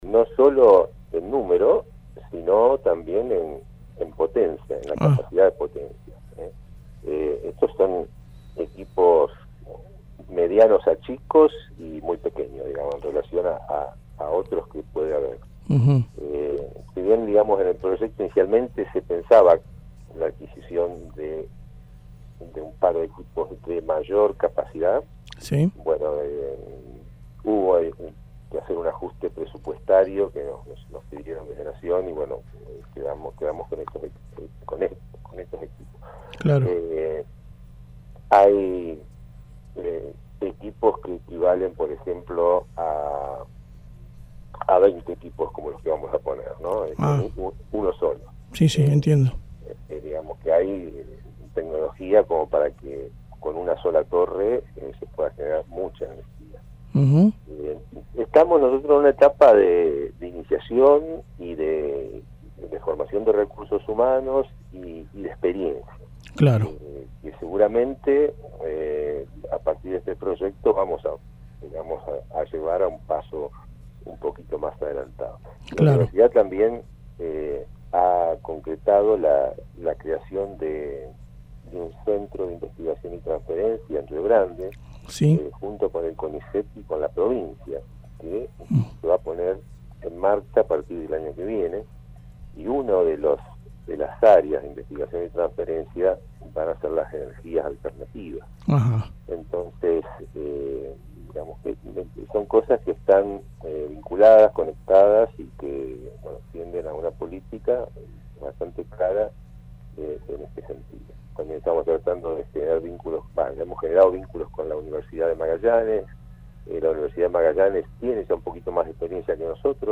dialogó con Radio Nacional y contó como nace este del proyecto y destacó la próxima llegada de los equipos adquiridos por la Universidad.